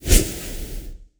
freeze_cam.wav